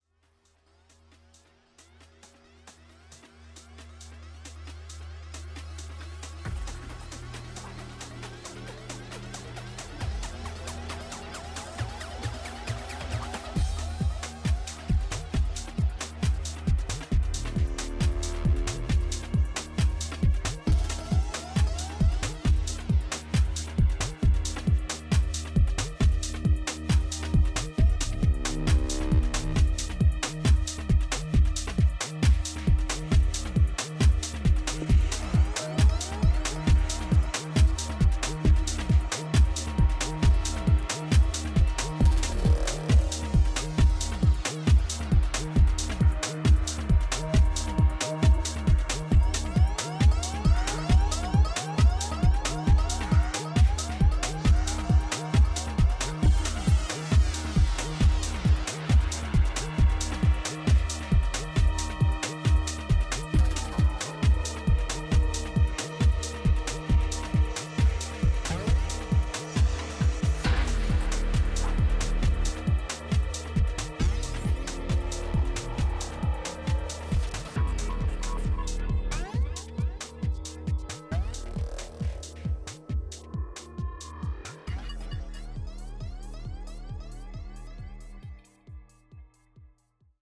オーガニックなテクスチャーを軸に卓越したグルーヴ感とサイケデリックな音響志向が見事に合致したテクノの新感覚を提示。